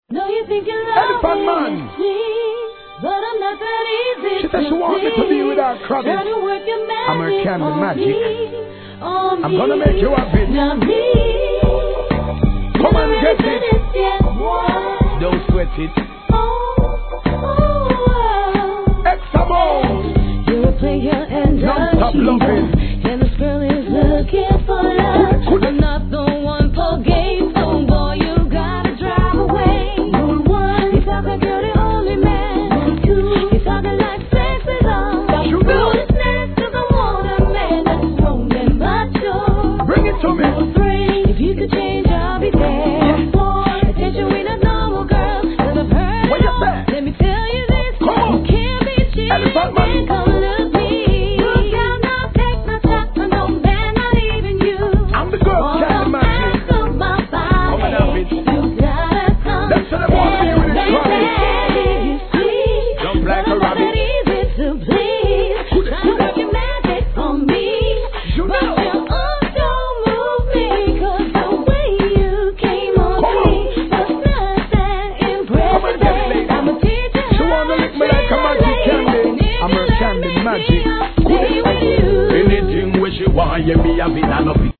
REGGAE
POPな仕上がりです!